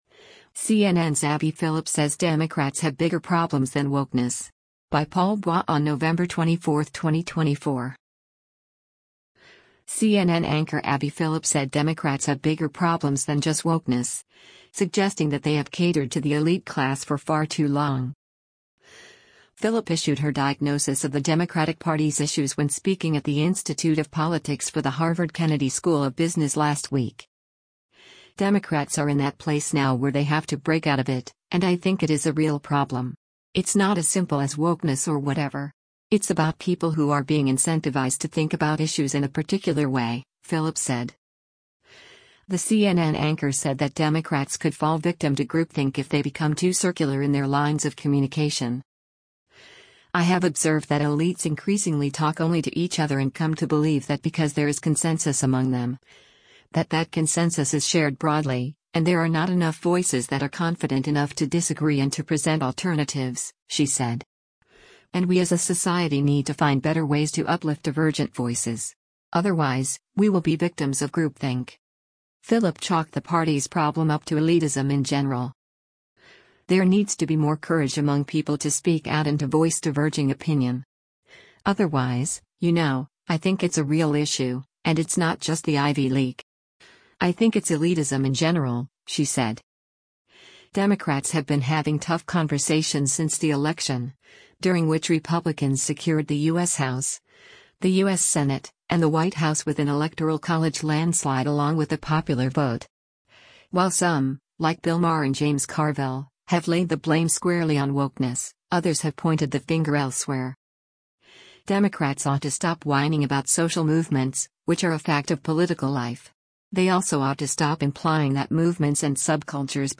Phillip issued her diagnosis of the Democratic Party’s issues when speaking at the Institute of Politics for the Harvard Kennedy School of Business last week.